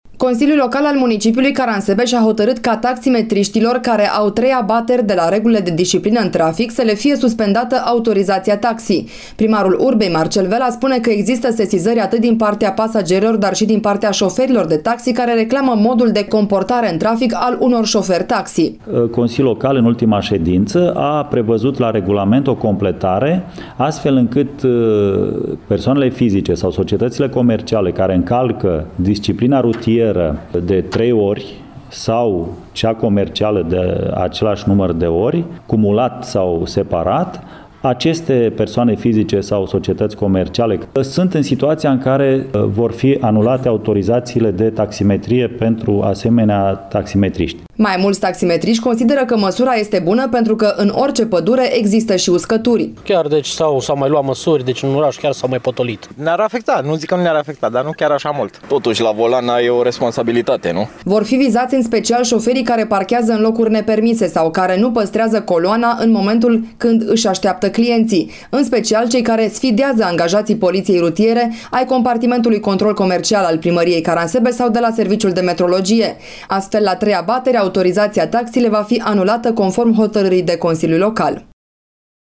Un reportaj